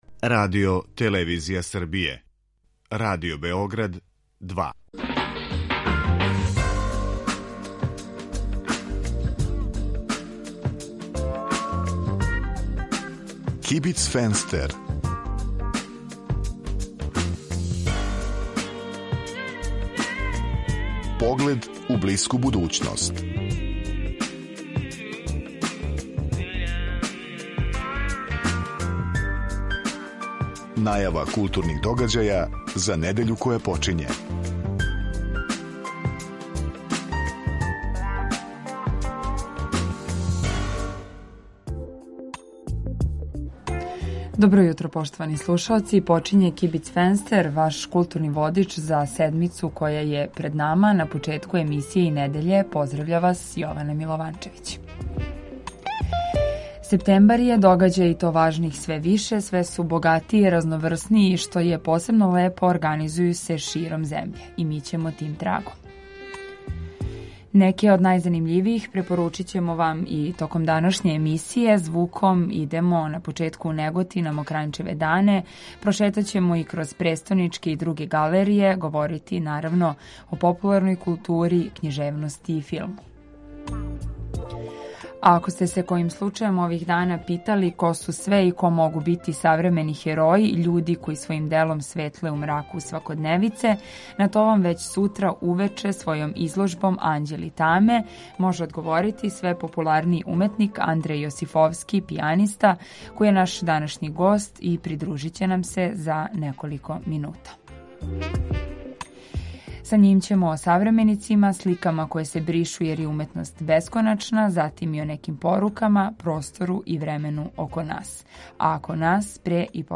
У некој врсти културног информатора за недељу која је почела, чућете аргументован избор и препоруку новинара и уредника РБ 2 из догађаја у култури који су у понуди у тој недељи. Свака емисија има и госта (госте), чији избор диктира актуелност – то је неко ко нешто ради у тој недељи или је везан за неки пројекат који је у току.